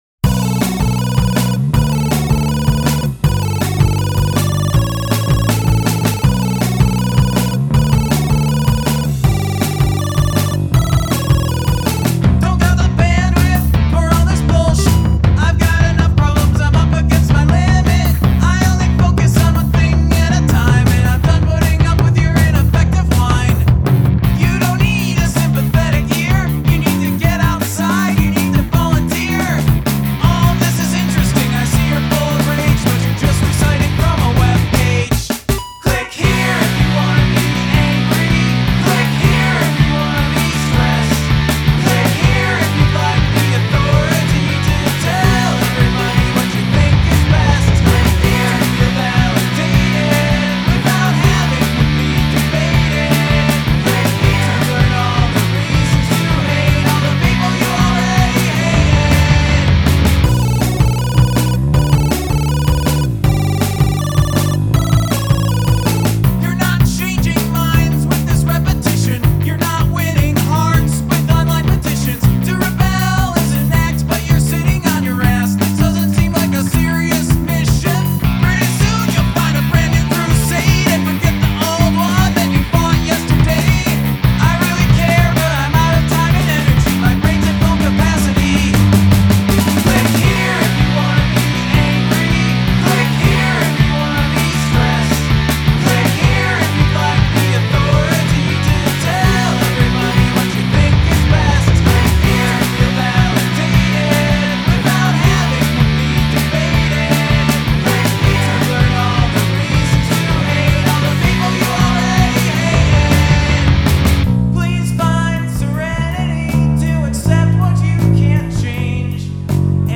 Elements of chiptune/ computer sounds